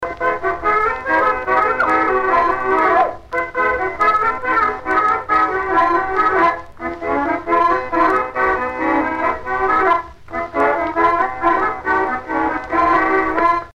Branle - 6
danse : branle : courante, maraîchine
Pièce musicale éditée